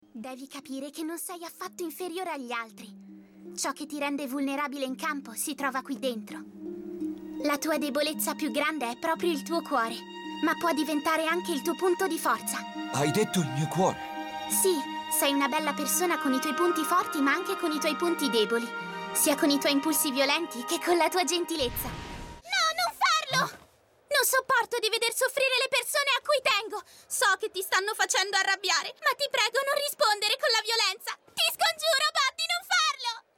cartone animato